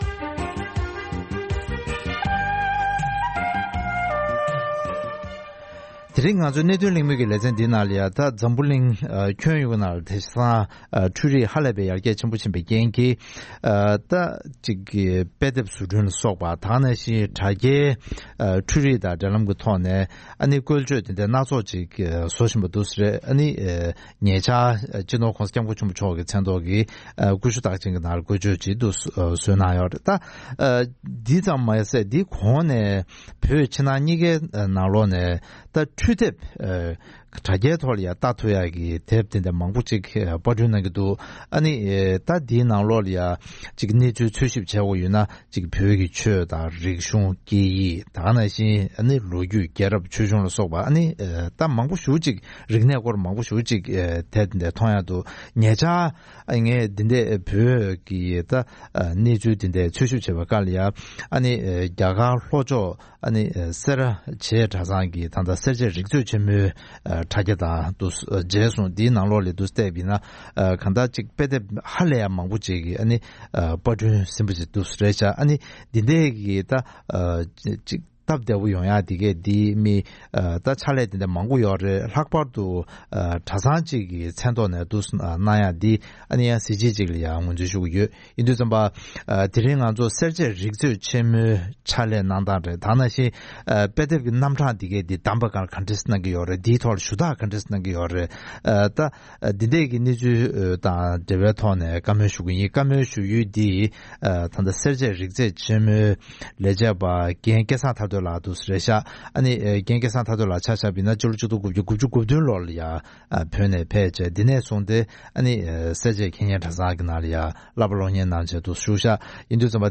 སེར་བྱེས་རིག་མཛོད་ཆེན་མོའི་ཕྱག་ལས་གནང་སྟངས་དང་དཔེ་དེབ་དཔར་བསྐྲུན་གནང་ཕྱོགས་ཐད་གླེང་མོལ།